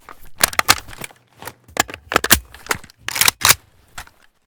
aek971_reload_empty.ogg